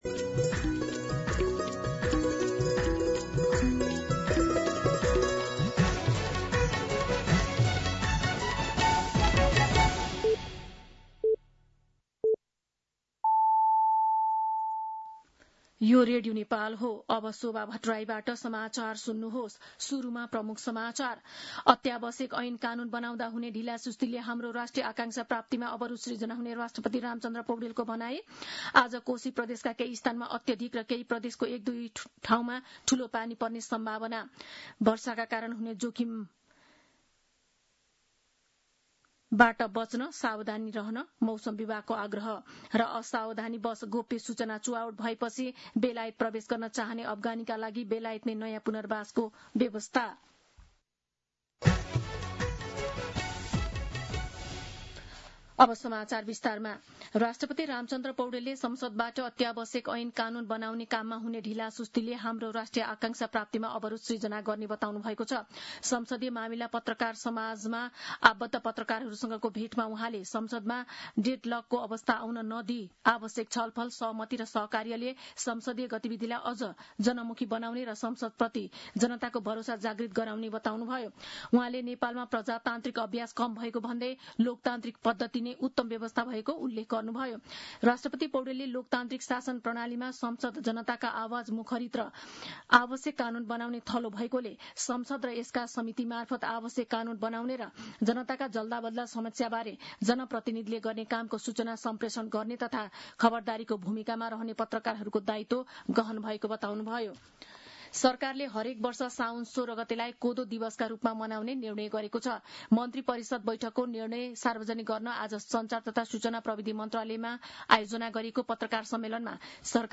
दिउँसो ३ बजेको नेपाली समाचार : ३२ असार , २०८२